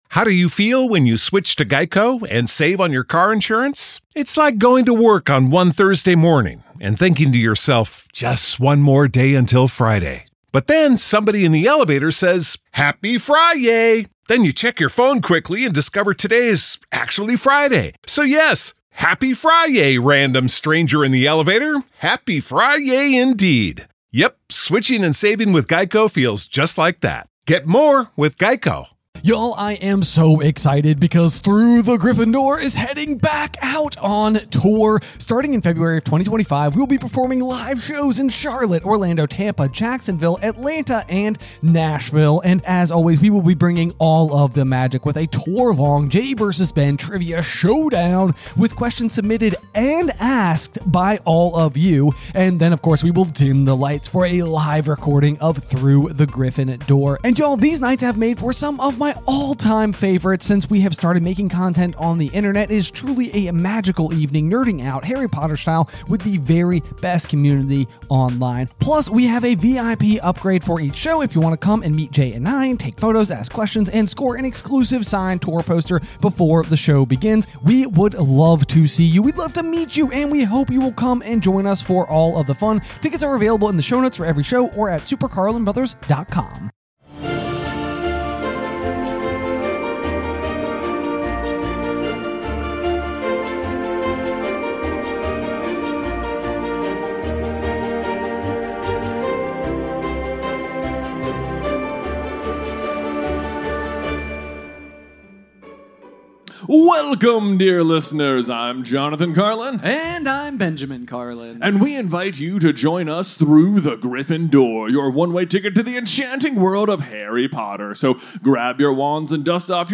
Live in Los Angeles - Chapter 2: The Scar | Goblet of Fire.